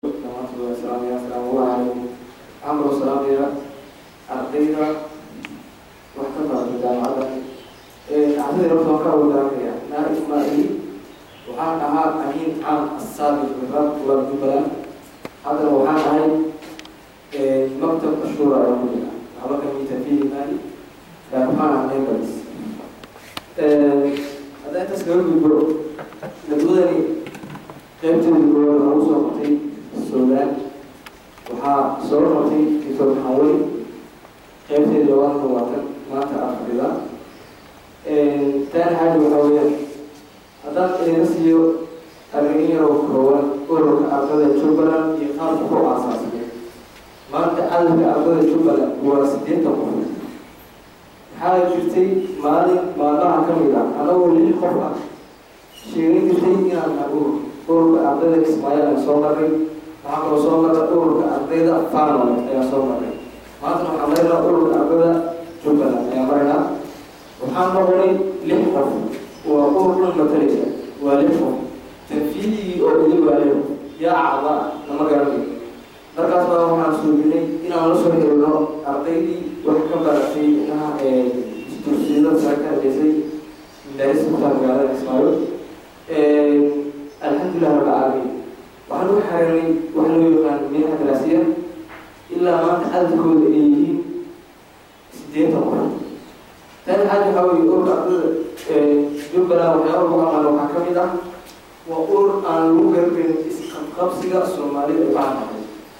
Dood cilmiyeedkan oo ah tii ugu horeysay oo ay soo qaban qaabiyaan Ardayda wax ka barata Jamacadaha ku yaala Dalka Suudan.